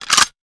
metahunt/weapon_foley_drop_19.wav at dfc221d77e348ec7e63a960bbac48111fd5b6b76
weapon_foley_drop_19.wav